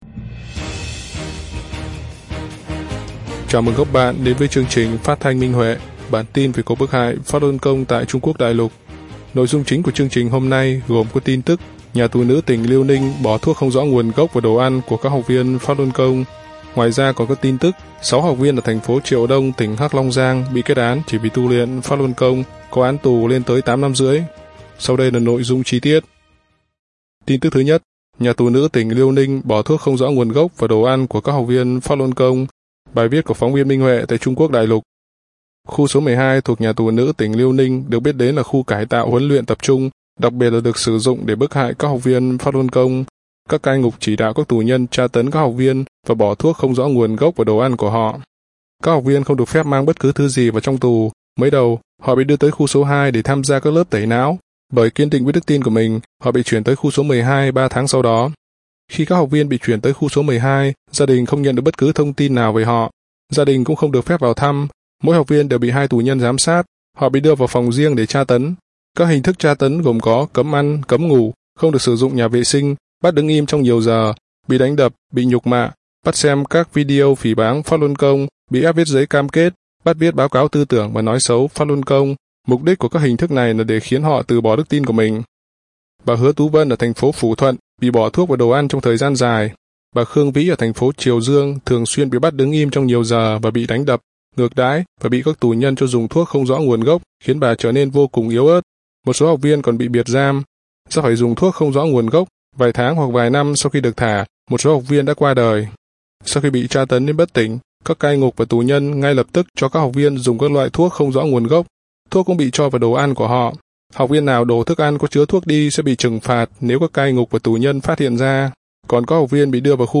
Chương trình phát thanh số 73: Tin tức Pháp Luân Đại Pháp tại Đại Lục – Ngày 02/02/2024